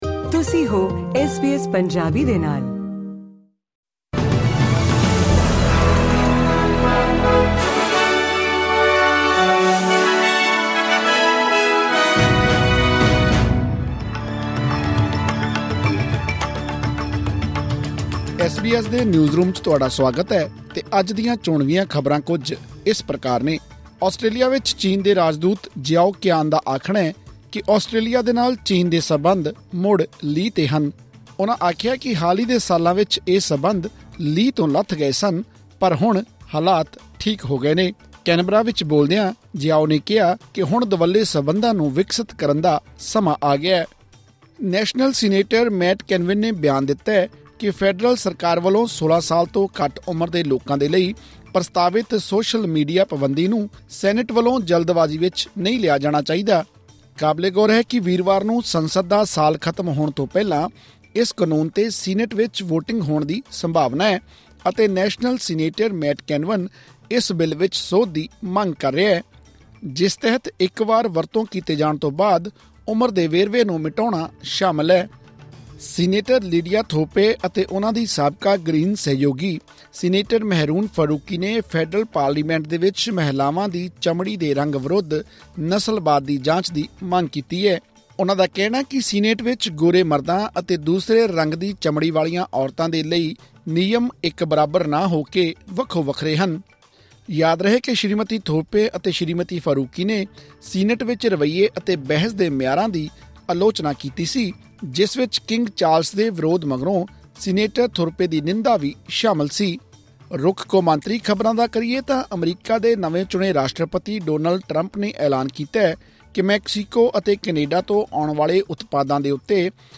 ਐਸ ਬੀ ਐਸ ਪੰਜਾਬੀ ਤੋਂ ਆਸਟ੍ਰੇਲੀਆ ਦੀਆਂ ਮੁੱਖ ਖ਼ਬਰਾਂ: 26 ਨਵੰਬਰ, 2024